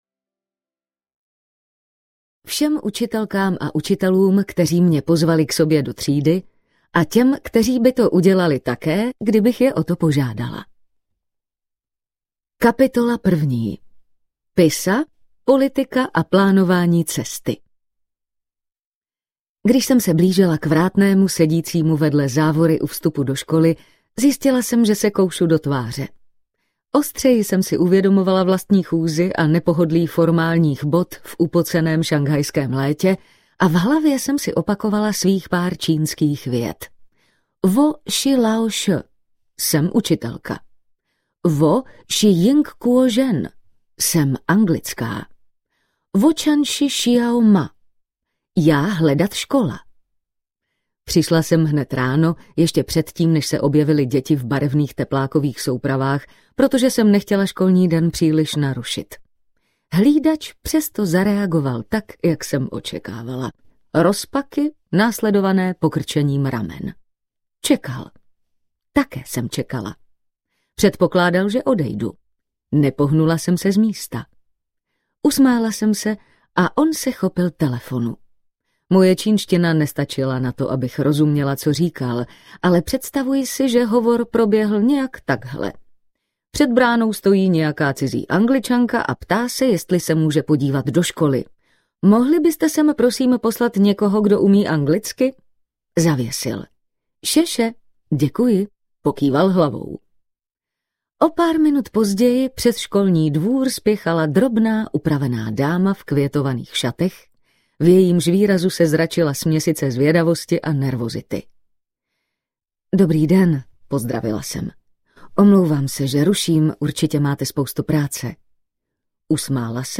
Chytrozemě audiokniha
Ukázka z knihy